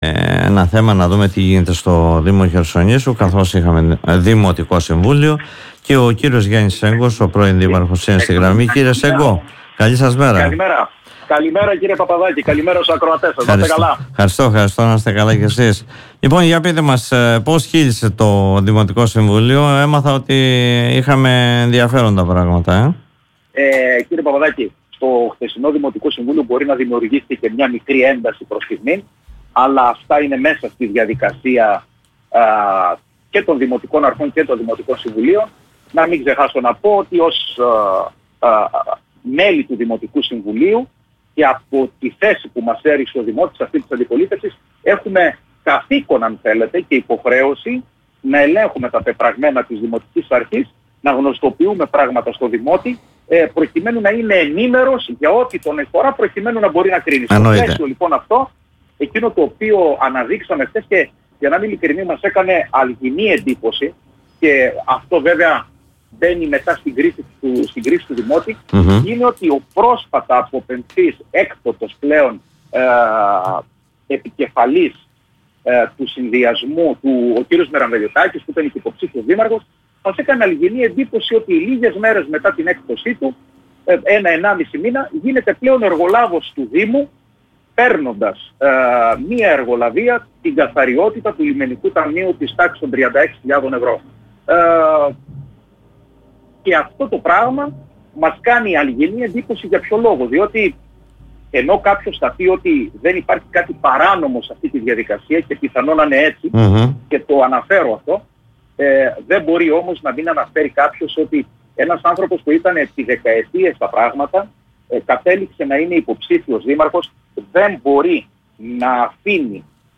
Ο κ. Σέγκος μιλώντας στην εκπομπή “Δημοσίως”